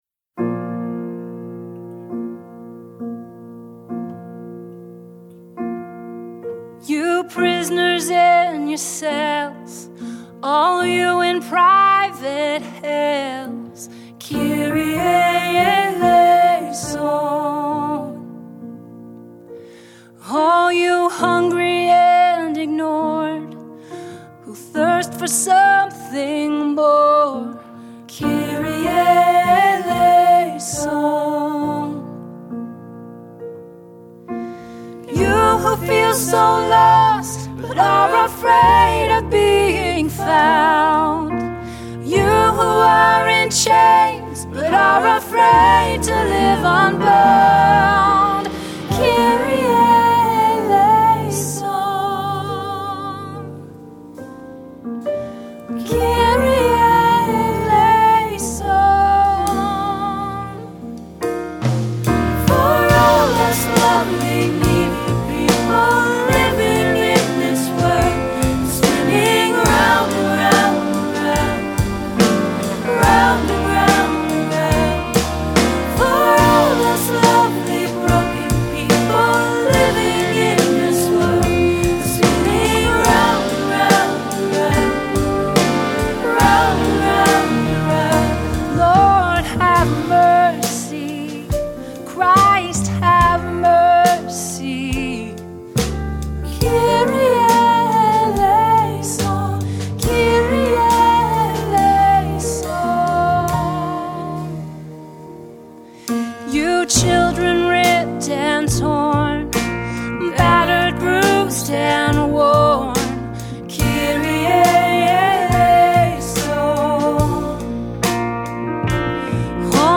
soulful prayer for mercy and solidarity